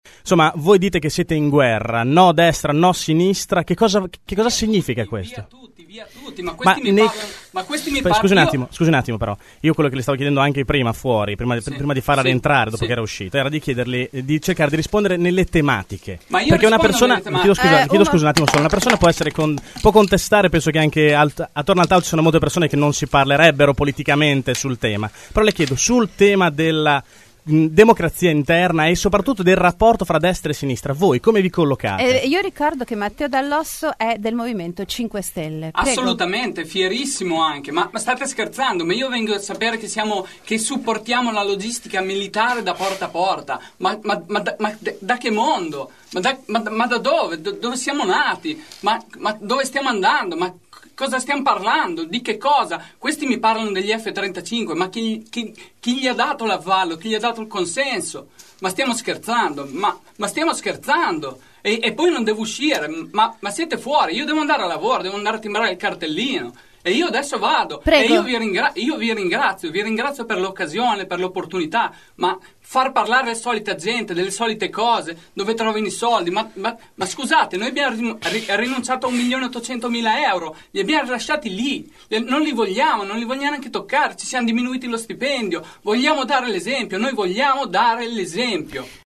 Dall’Osso è poi rientrato e poco dopo, mentre rispondeva ad una domanda su come si ponga il movimento 5 stelle rispetto alla questione destra/sinistra ha lasciato nuovamente lo studio dicendo: “Ma stiamo scherzando?